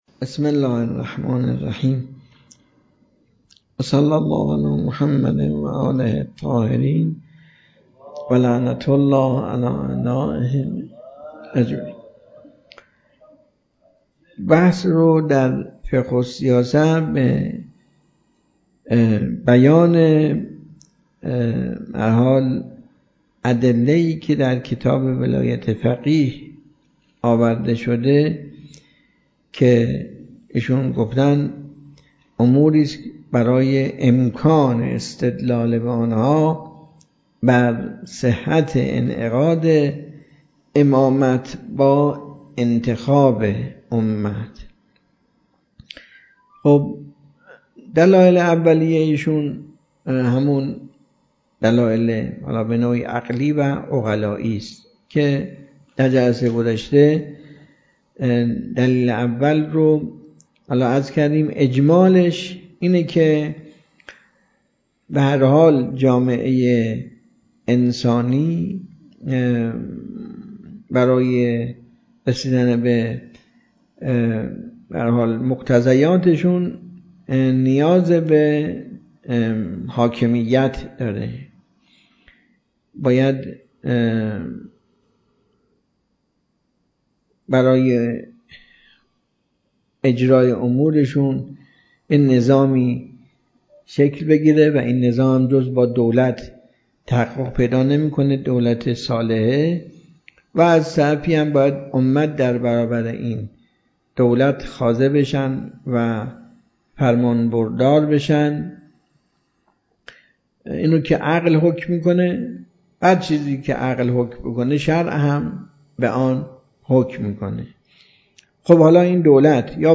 جلسه نوزدهم خارج فقه ۰۴آذر۱۴۰۲